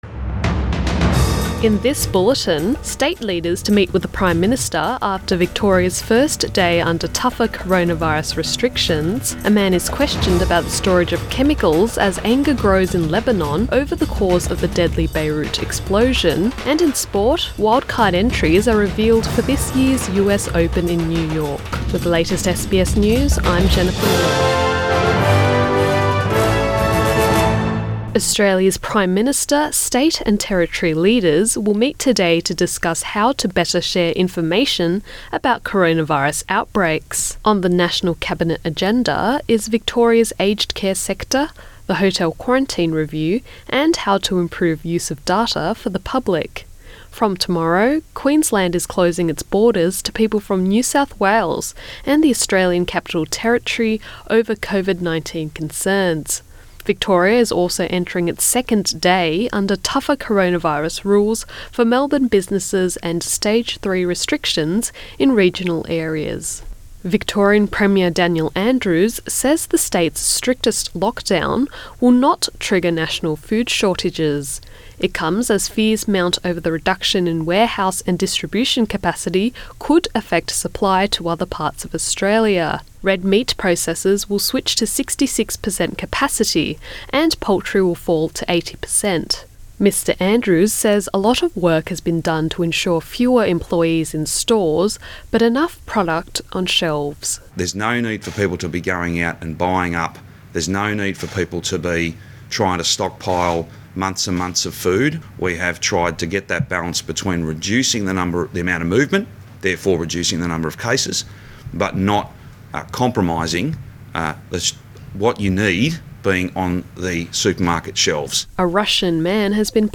AM bulletin 7 August 2020